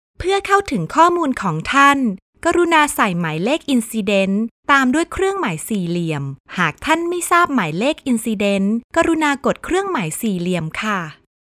I have a home recording studio with high quality audio equipments such as Nueman TLM 103 microphone, Focusrite Class A preamp, KRK V6 studio monitor and Digidesign Mbox.
I deliver all recording with no breath no noise edited audio.
Sprechprobe: eLearning (Muttersprache):